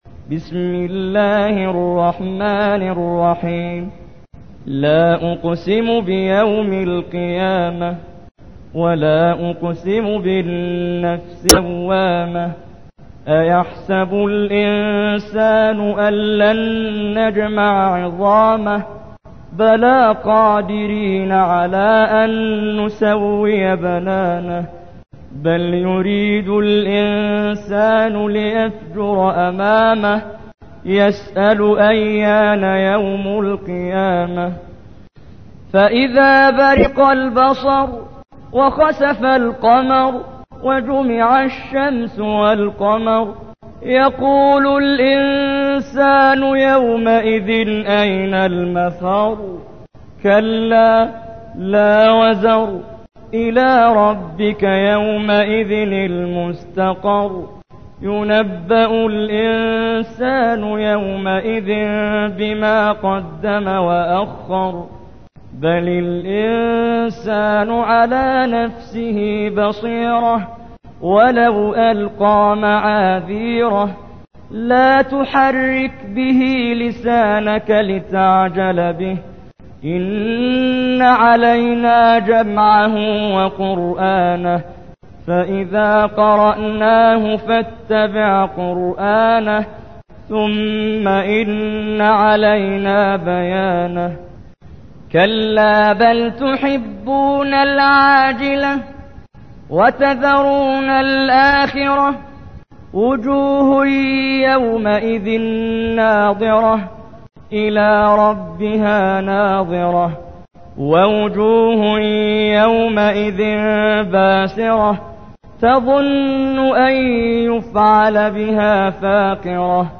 تحميل : 75. سورة القيامة / القارئ محمد جبريل / القرآن الكريم / موقع يا حسين